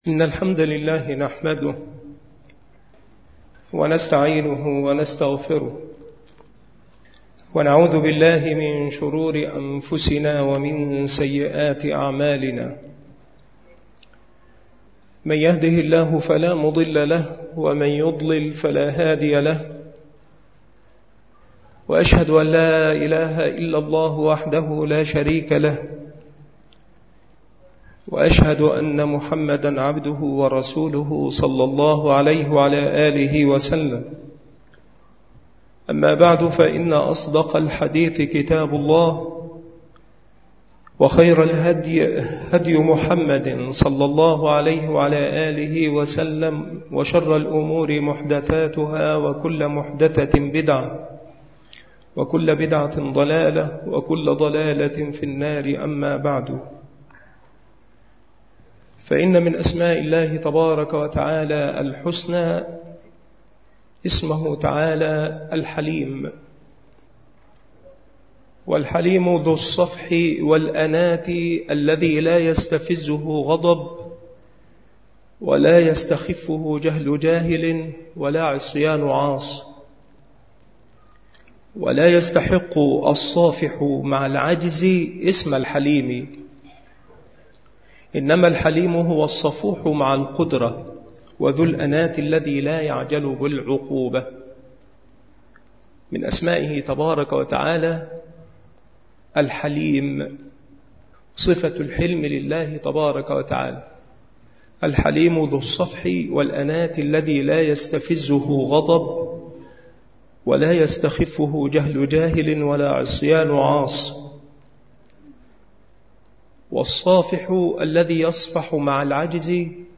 المحاضرة
مكان إلقاء هذه المحاضرة بالمسجد الشرقي بسبك الأحد - أشمون - محافظة المنوفية - مصر